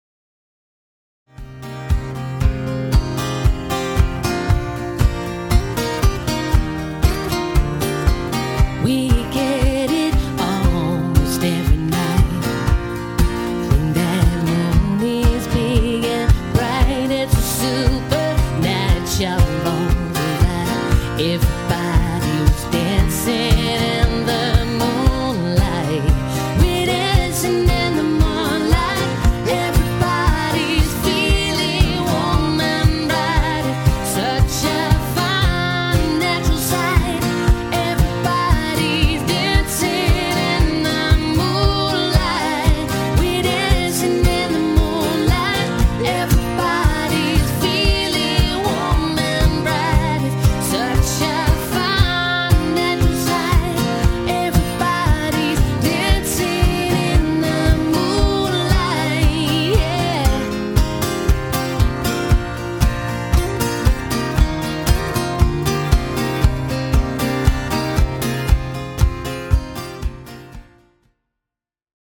• Male & female vocal harmonies
(Country Style)